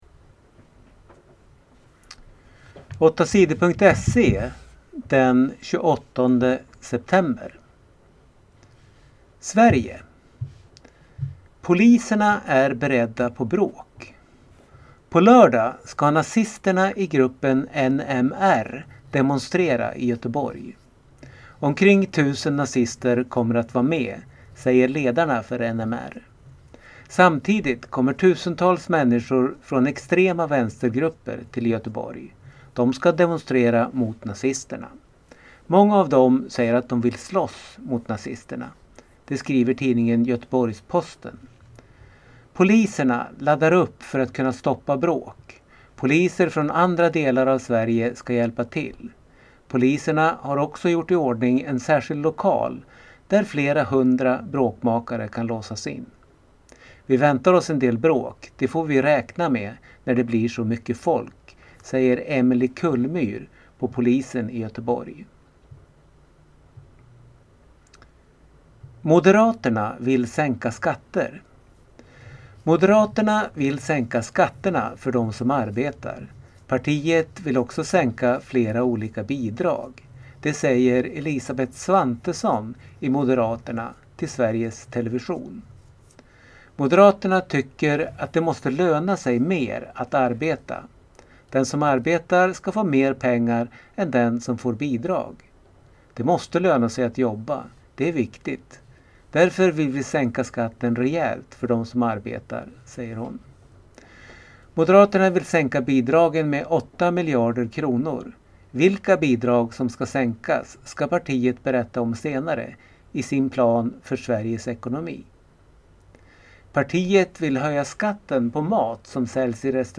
Lyssna på nyheter från torsdagen den 28 september